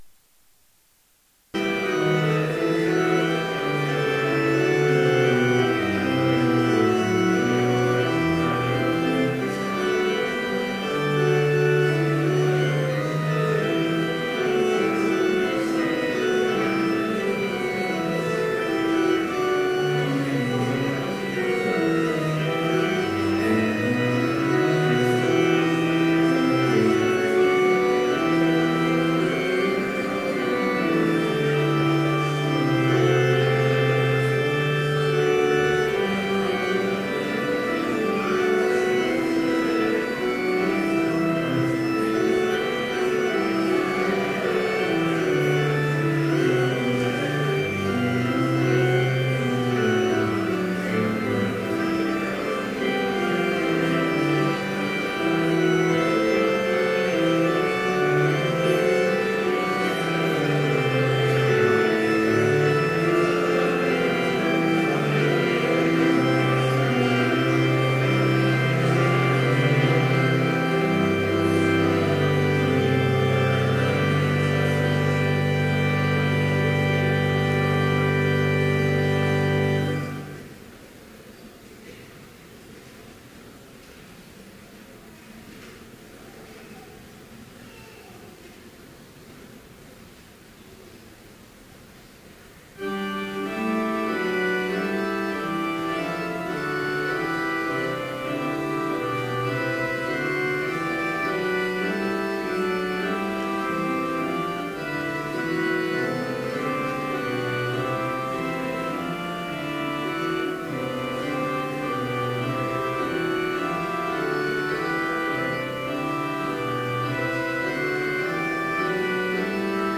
Complete service audio for Chapel - November 21, 2013